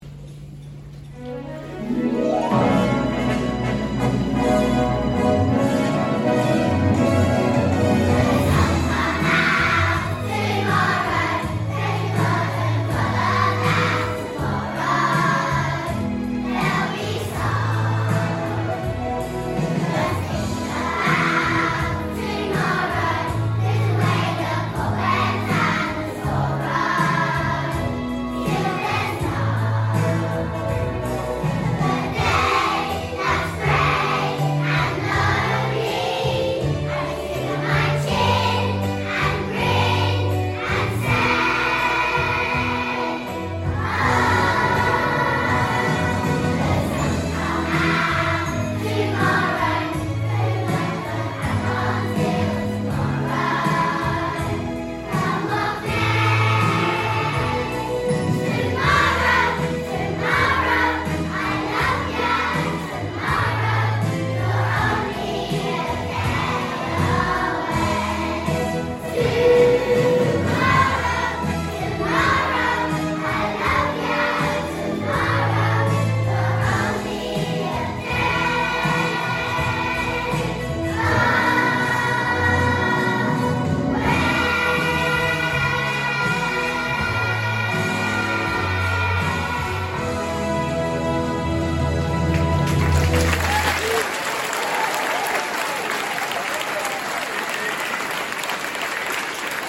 Tomorrow | Y2/3 Choir